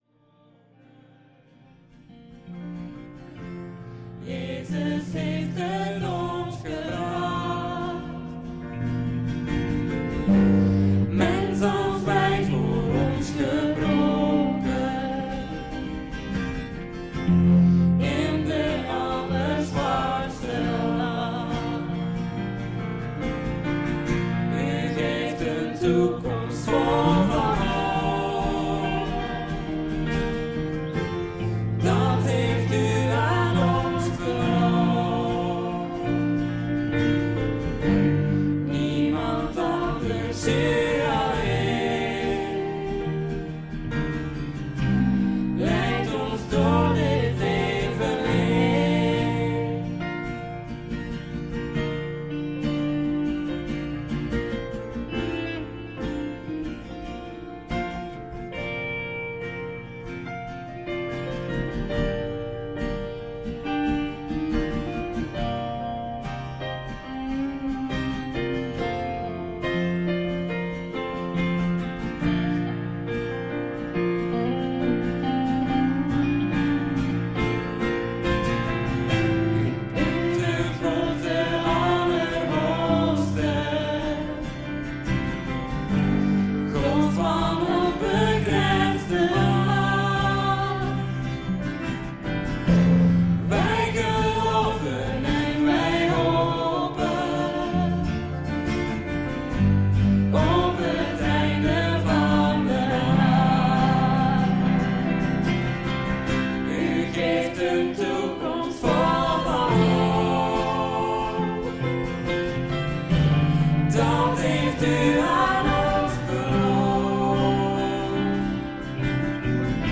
(Om direct de preek te beluisteren: schuif met het pijltje van de muis lags de horizontale streep: je zut de cijfertjes zien verspringen ga ongeveer tot 24:00 en klik op de linker muisknop: druk daarna op play>> driehoekje)